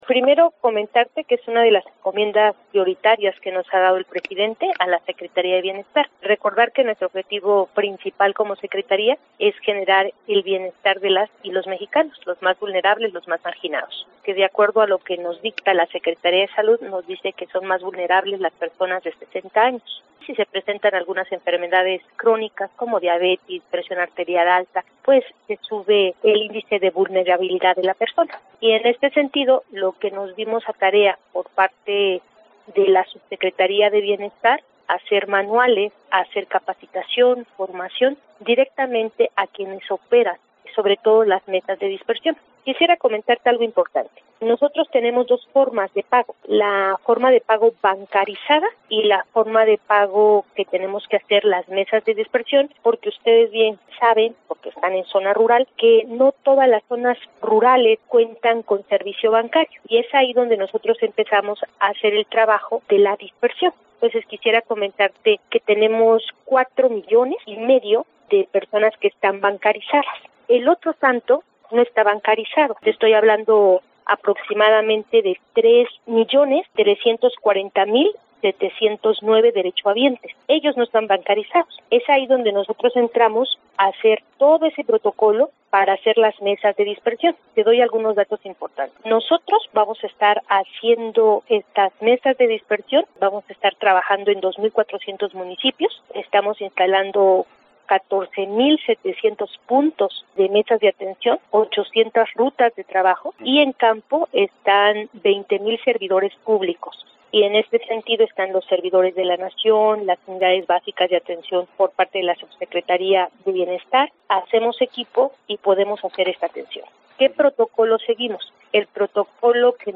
En entrevista con María luisa Albores González, Secretaria del Bienestar del Gobierno Federal, da a conocer las medidas tomadas para evitar exponer a los adultos mayores en la entrega de apoyos ante la contingencia sanitaria que está pasando nuestro país por la pandemia del coronavirus.